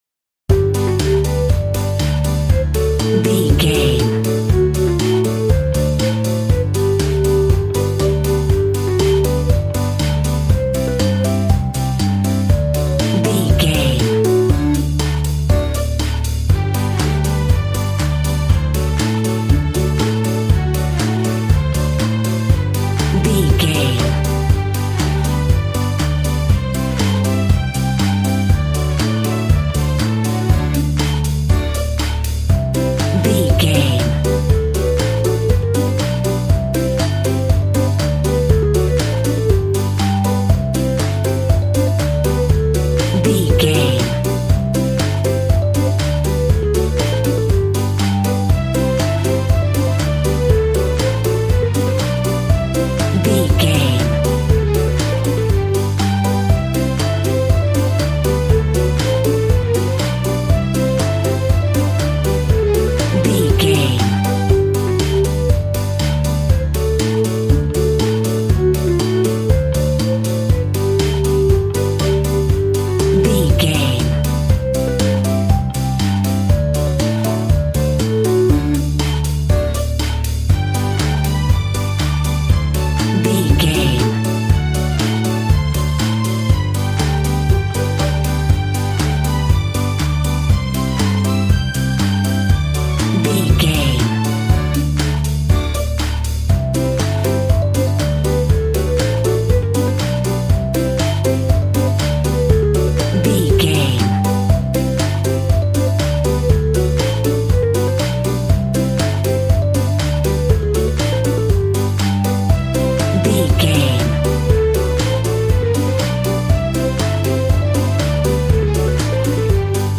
Uplifting
Ionian/Major
Fast
instrumentals
fun
childlike
happy
kids piano